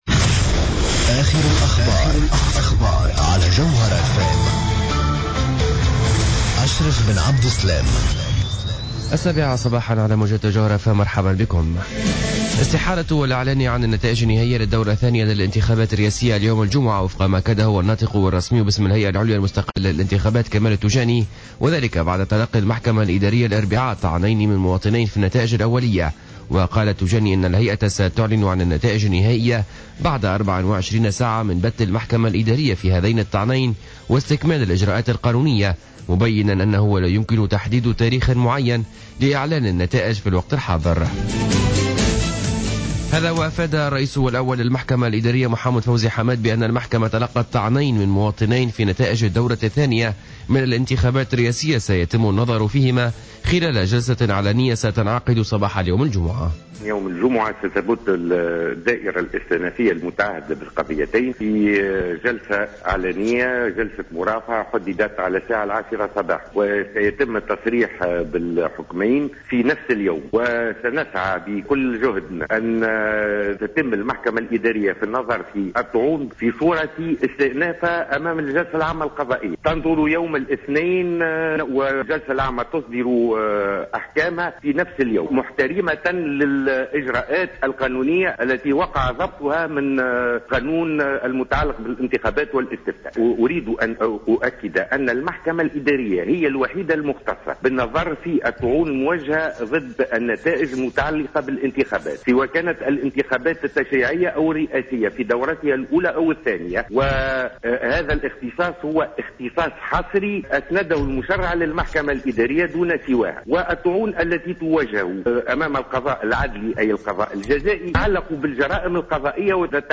نشرة اخبار السابعة صباحا ليوم الجمعة 26-12-14